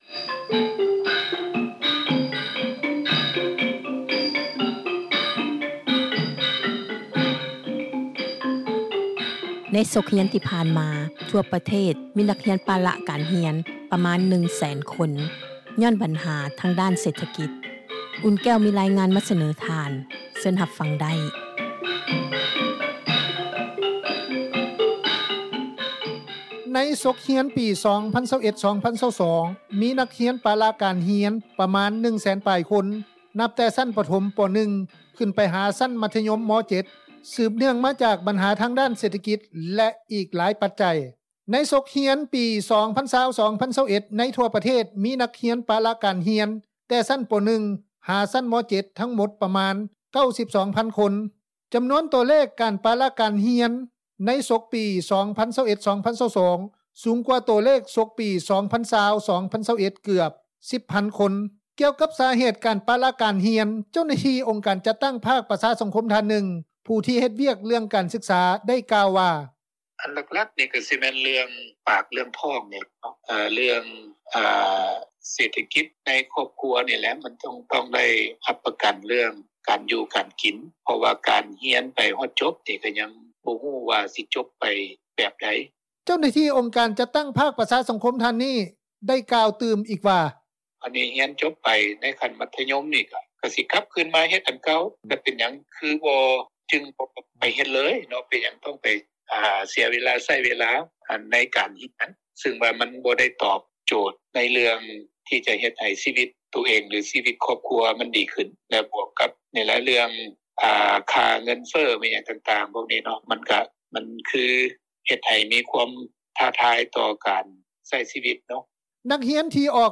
ກ່ຽວກັບສາເຫດ ການປະລະການຮຽນ, ເຈົ້າໜ້າທີ່ອົງການຈັດຕັ້ງ ພາກປະຊາສັງຄົມ ທ່ານ ນຶ່ງ ຜູ້ທີ່ເຮັດວຽກເຣື່ອງການສຶກສາ ໄດ້ກ່າວວ່າ:
ປະຊາຊົນ ຢູ່ແຂວງພາກໃຕ້ ທ່ານນຶ່ງ ໄດ້ໃຫ້ສໍາພາດຕໍ່ວິທຍຸ ເອເຊັຽເສຣີ ວ່າ: